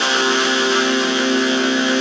45 SYNTH 2-R.wav